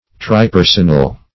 Search Result for " tripersonal" : The Collaborative International Dictionary of English v.0.48: Tripersonal \Tri*per"son*al\ (tr[-i]*p[~e]r"s[u^]n*al), a. [Pref. tri- + personal.]
tripersonal.mp3